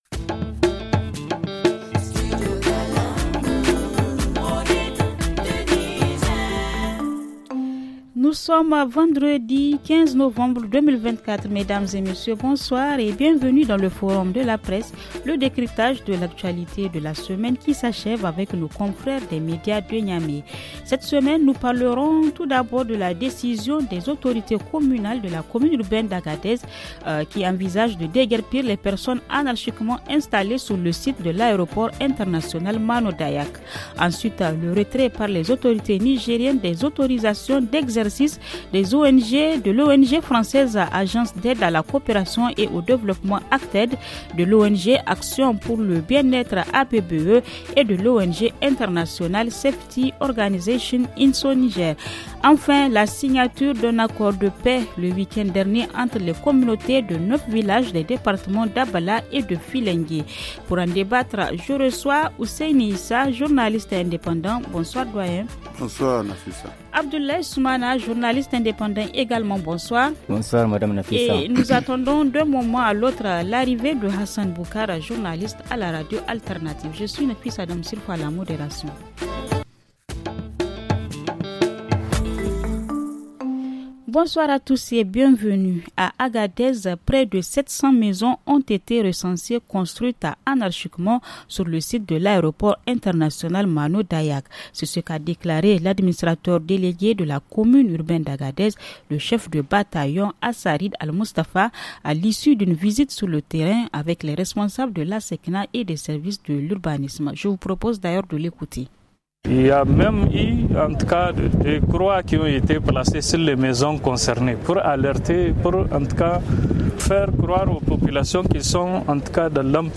Les sujets qui ont marqué l’actualité au Niger sont :